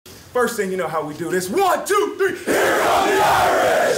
here come the irish Meme Sound Effect